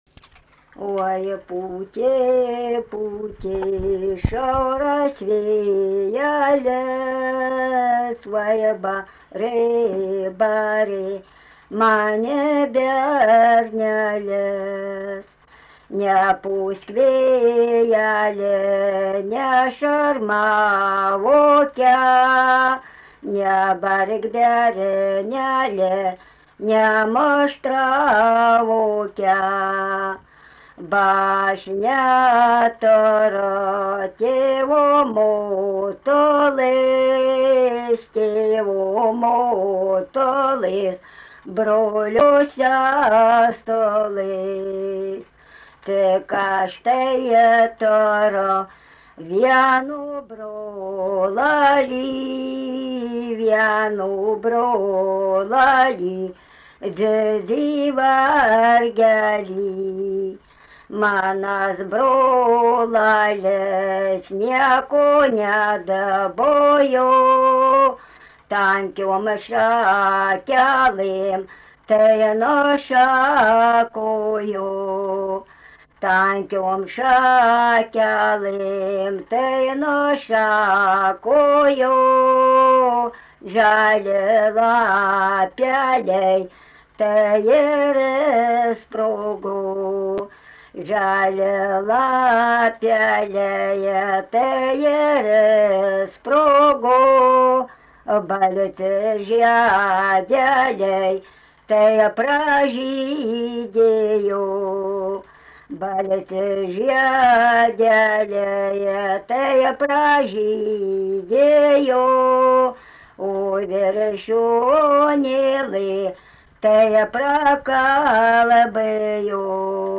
Subject daina
Erdvinė aprėptis Rudnia
Atlikimo pubūdis vokalinis